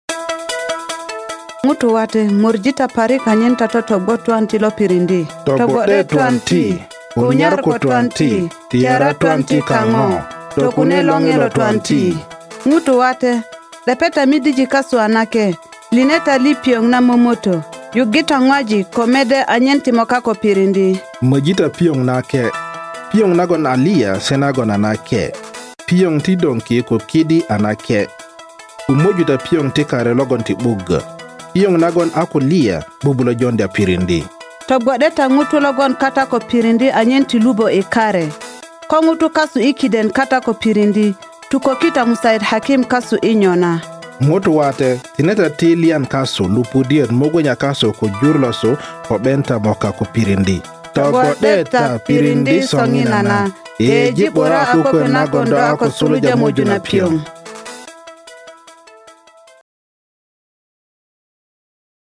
Guinea Worm Public Service Announcements (PSAs)
The Center's Guinea Worm Eradication Program and Office of Public Information developed 12 public service announcements to be read by native African speakers: first to be aired in English, Hausa, and French, followed by Arabic, Fulani, and Bambara.